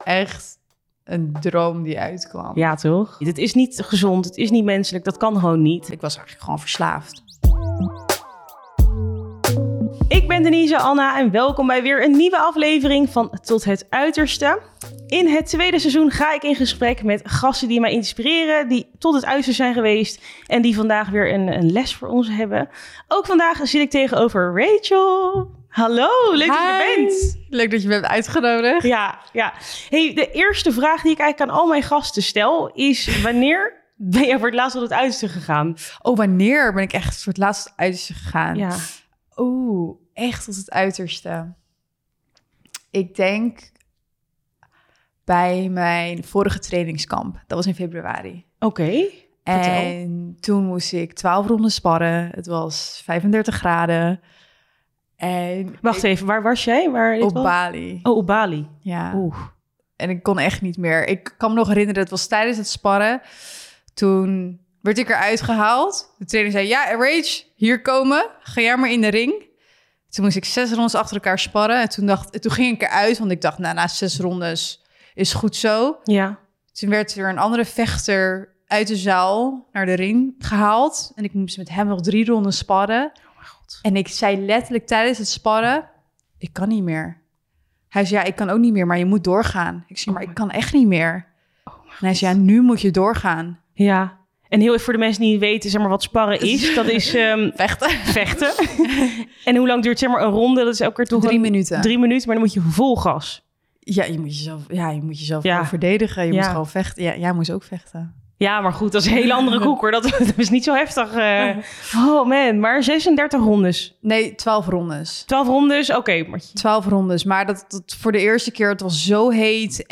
in gesprek met medecursisten van het televisieprogramma 'Kamp van Koningsbrugge'.